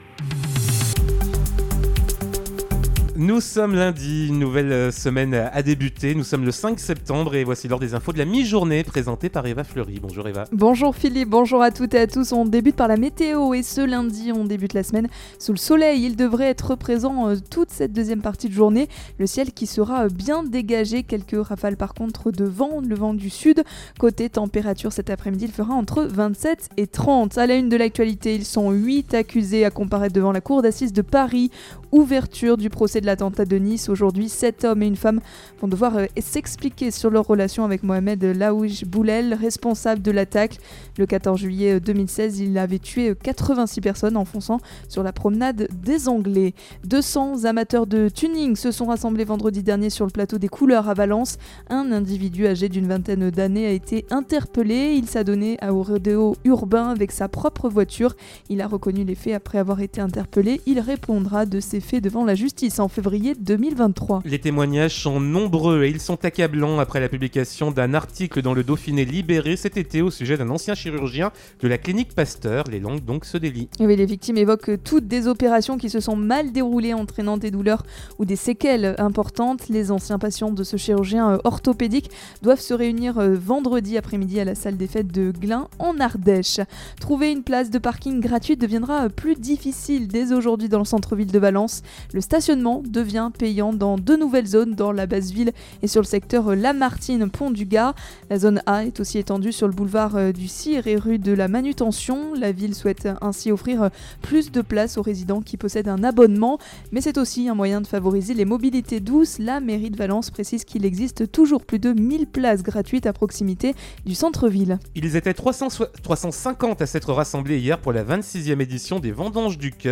in Journal du Jour - Flash